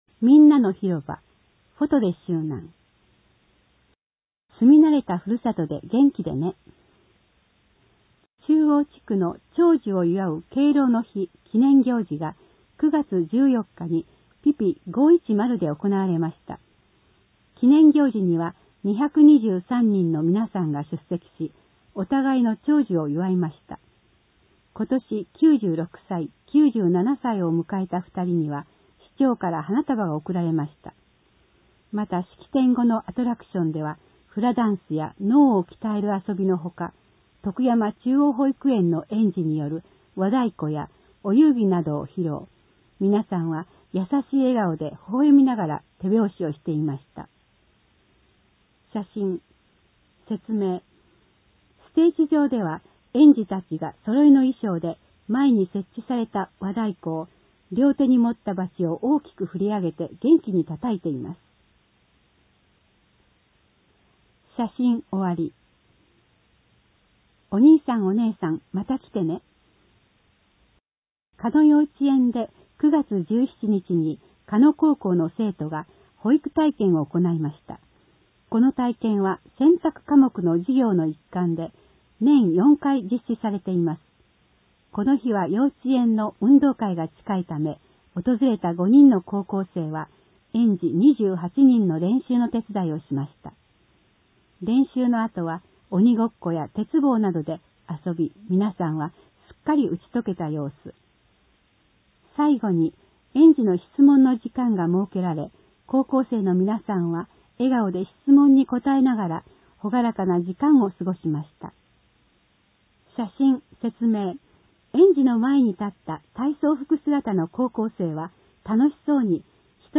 音訳広報
広報しゅうなんを、音読で収録し、mp3形式に変換して配信します。
この試みは、「音訳ボランティアグループともしび」が、視覚障害がある人のために録音している音読テープを、「点訳やまびこの会」の協力によりデジタル化しています。